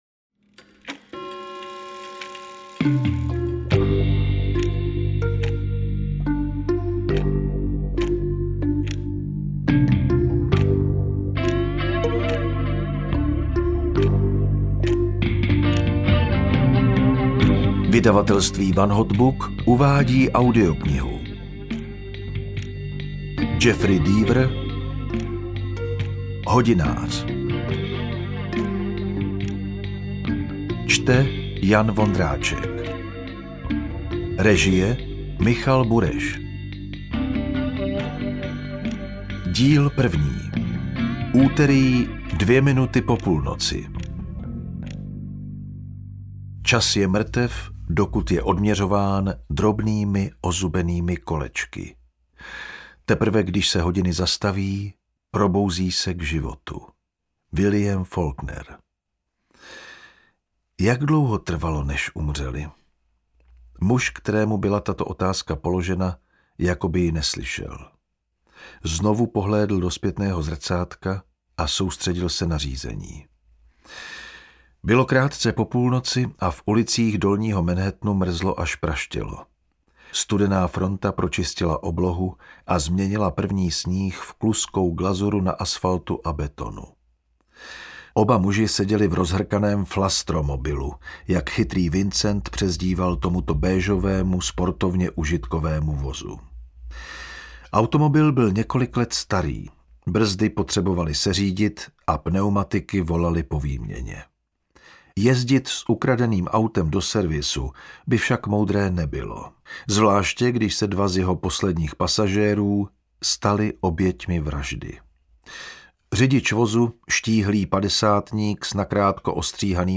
Interpret:  Jan Vondráček